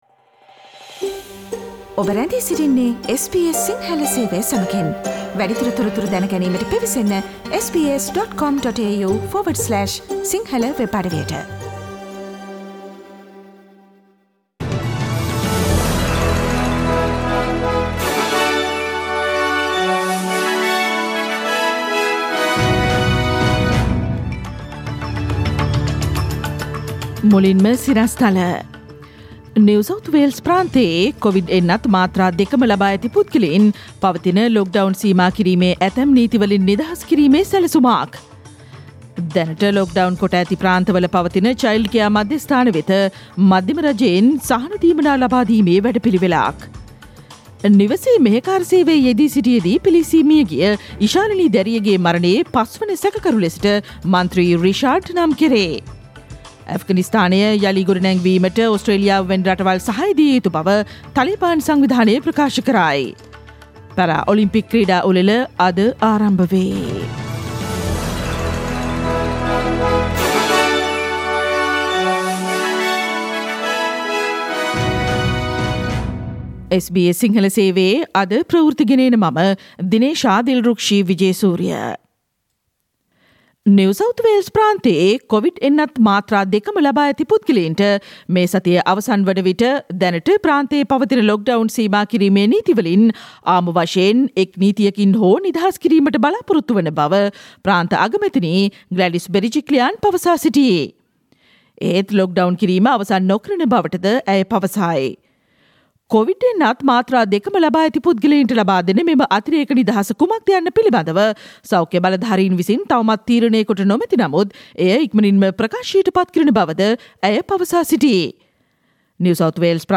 SBS Sinhala Radio News on 24 Aug 2021: People who got both Covid 19 vaccines in NSW are likely to be exempt from certain lockdown rules
Listen to the latest news from Australia, Sri Lanka, across the globe and the latest news from sports world on SBS Sinhala radio news bulletin – Tuesday 24 August 2021